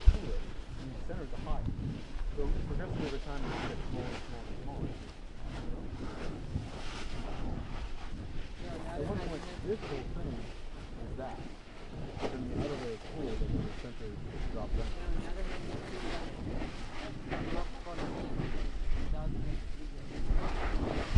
焚烧塑料袋Zilch的声音5
描述：几个塑料"zilches"着火的有趣声音。背景是鸟儿和其他自然界的声音。 2月21日清晨，克拉克福克河附近。
Tag: 小人物 塑料 消防 现场录音 NOIS Ë